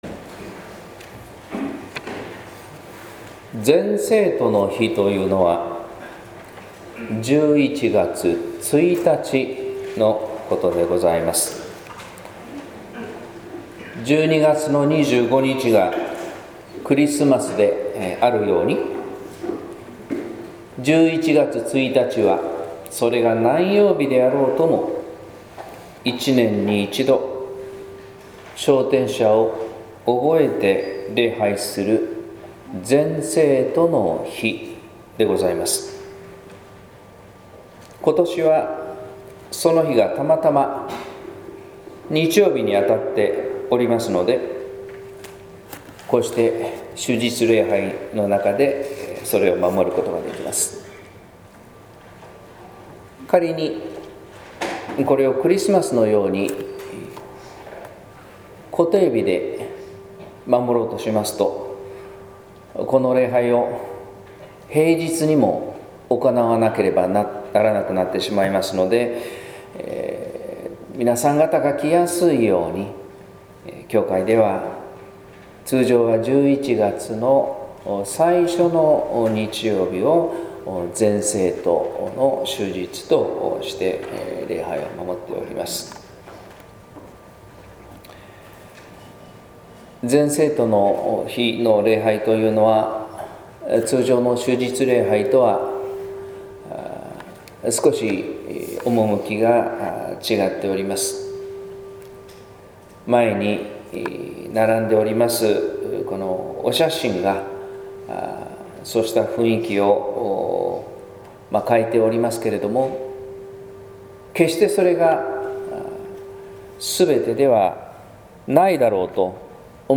説教「死を知る」（音声版）